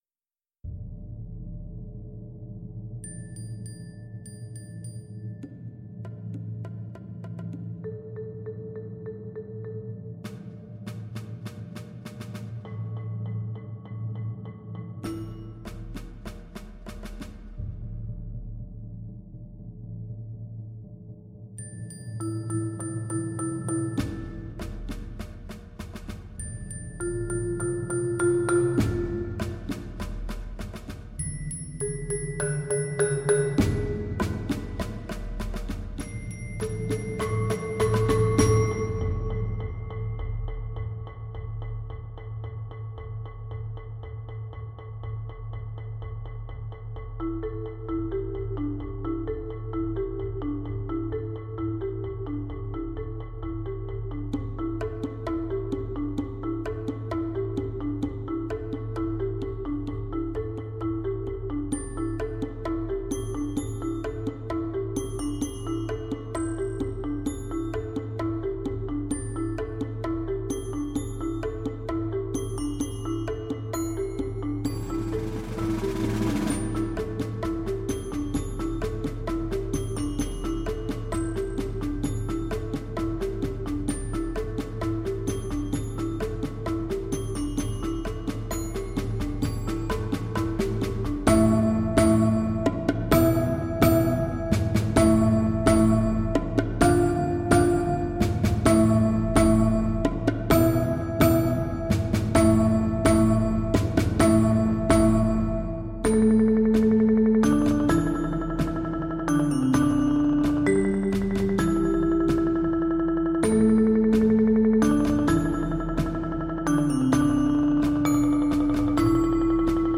Voicing: Percussion Septet